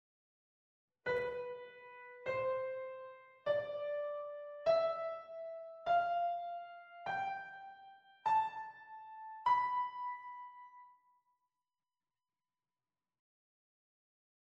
Locrian Mode
locrian-mode.mp3